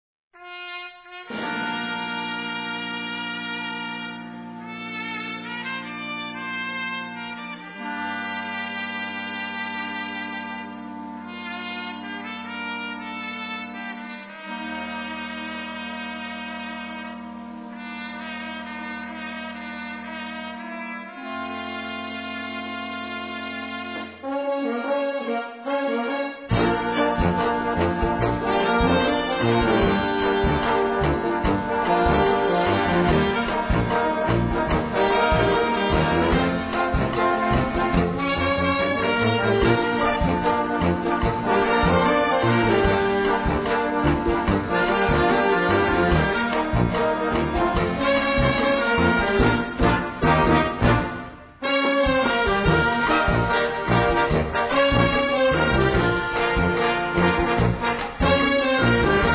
Gattung: Rumba
Besetzung: Blasorchester